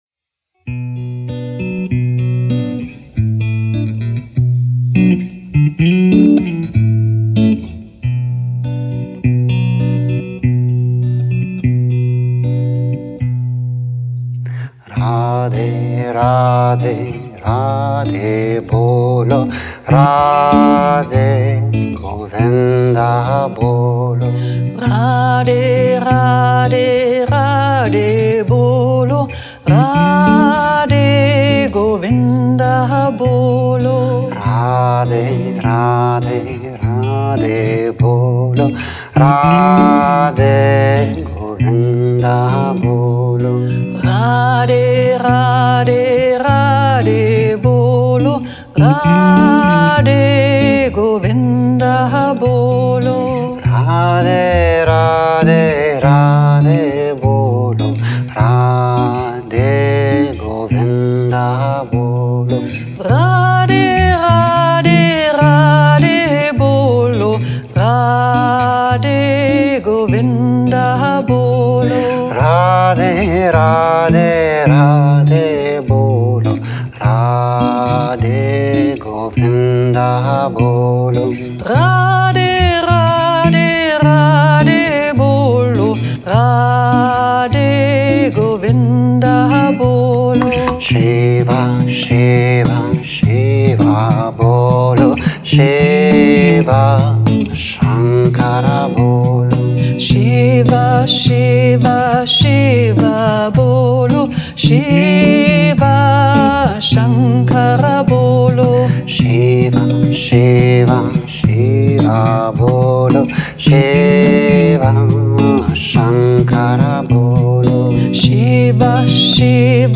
Gesang & Cajonito Dies ist ein besonders
dynamischer, energiegeladener Kirtan.
Mantra_Circle-Radhe_Radhe_Radhe_Bolo.mp3